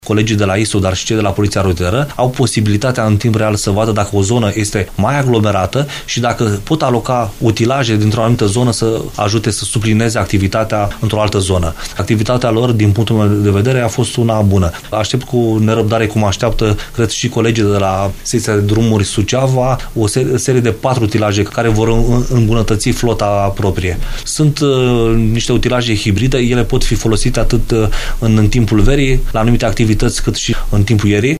Prefectul ALEXANDRU MOLDOVAN a declarat postului nostru că Secțiile Drumuri Naționale Suceava și Câmpulung Moldovenesc vor primi, în acest an, 4 utilaje moderne, care vor putea fi folosite atât pentru întreținerea, cât și pentru deszăpezirea căilor rutiere.